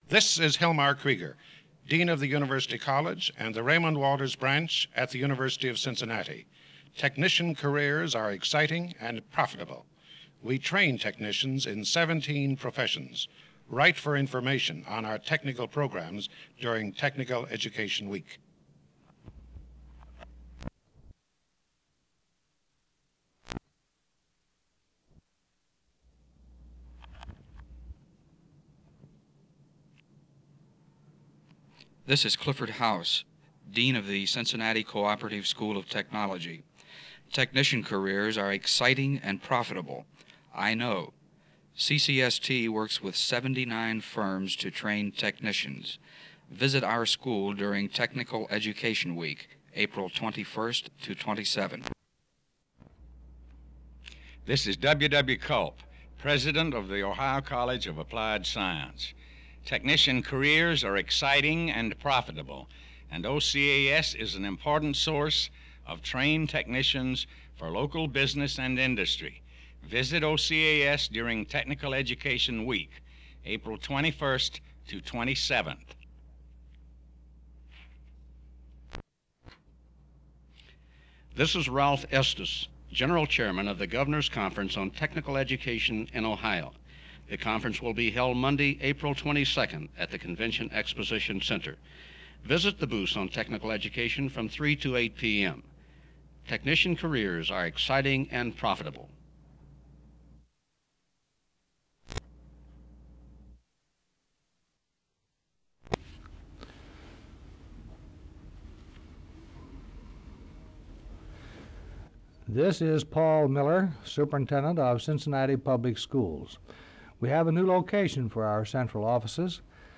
These radio spots advertising Technical Education Week are from ca. 1966-1969.